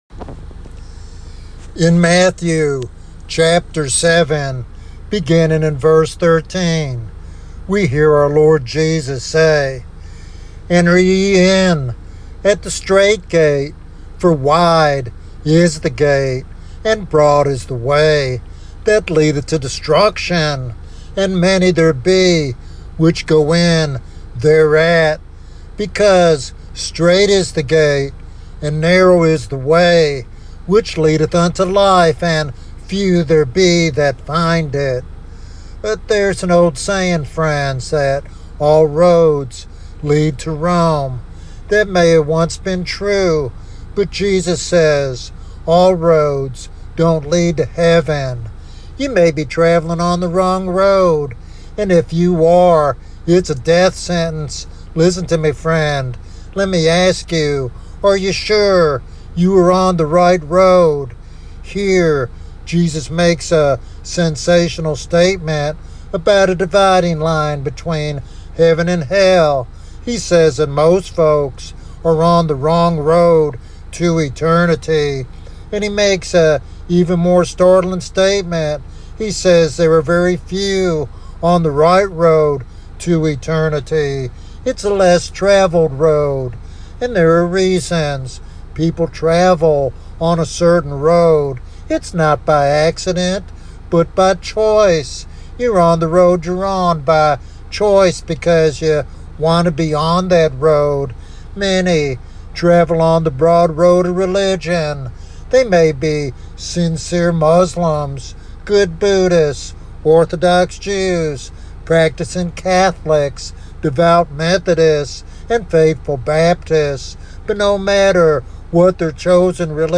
This sermon calls individuals to examine their spiritual path and respond to the urgent invitation to follow the narrow way to eternal life.